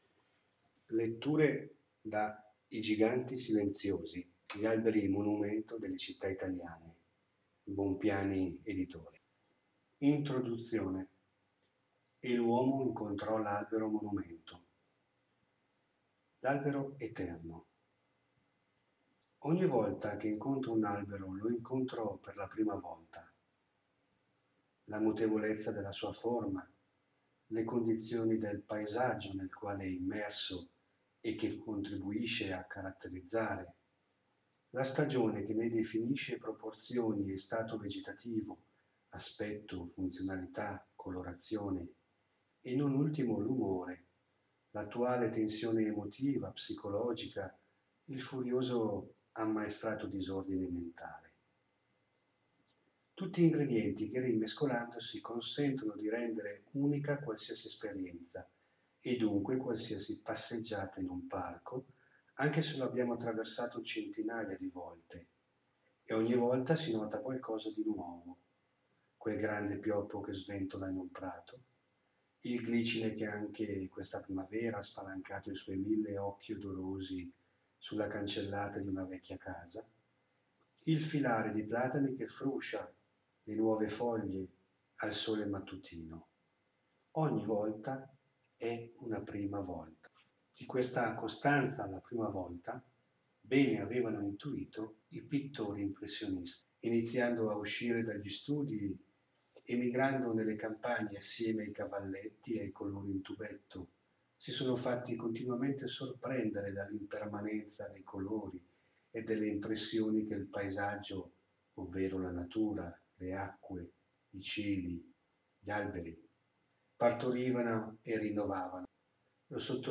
A seguire una selezione di brani e passi letti per voi.
Dettaglio: letture dall’introduzione, E l’uomo incontrò l’albero monumento, e i paragrafi L’albero eterno e Homo radicans.